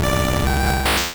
Cri d'Artikodin dans Pokémon Rouge et Bleu.